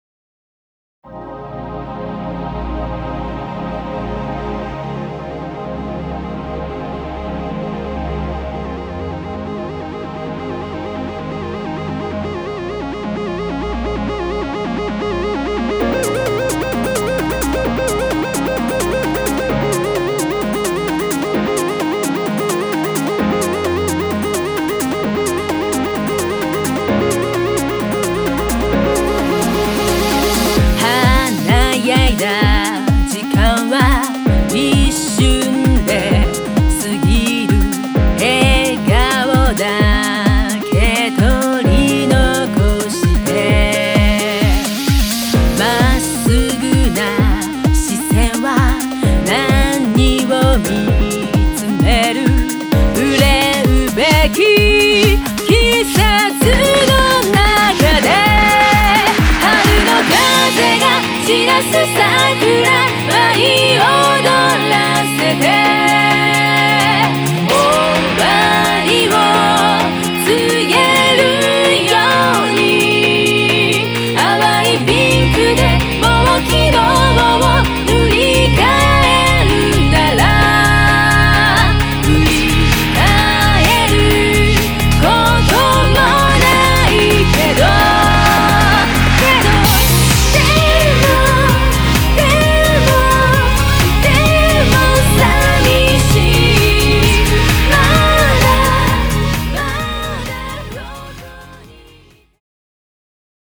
クロスフェードデモ
ポップあり、バラードあり、涙あり、情熱込みの8曲となっております！
春の空に華と奏でる、東方フルボーカルポップをどうぞお楽しみください！